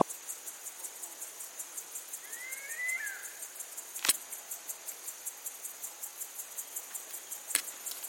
Grey-headed Kite (Leptodon cayanensis)
Life Stage: Adult
Location or protected area: Ruta 15 Biosfera Yaboti
Condition: Wild
Certainty: Recorded vocal